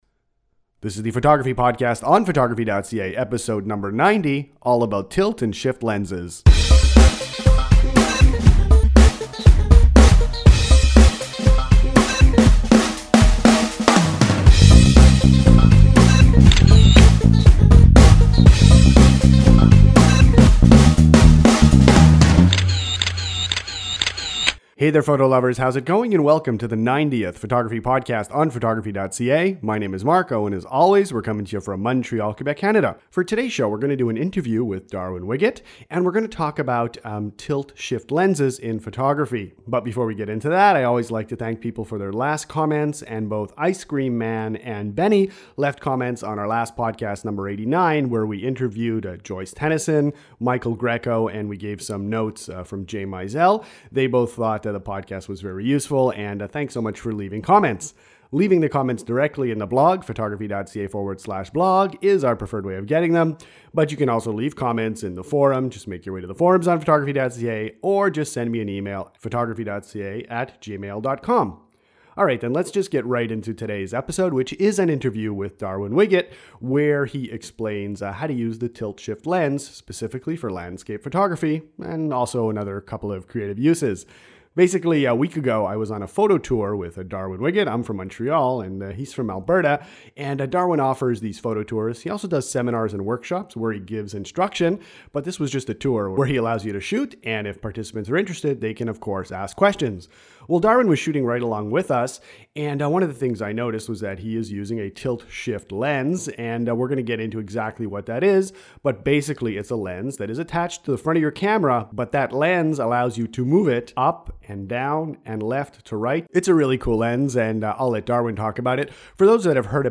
Tilt shift lenses in photography - Interview